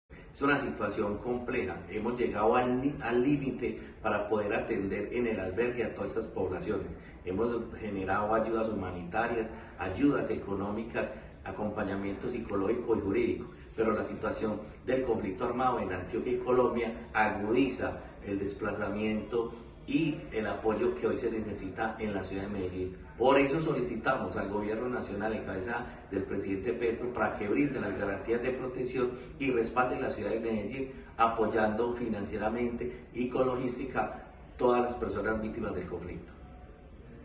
Palabras de Carlos Alberto Arcila, secretario de Paz y Derechos Humanos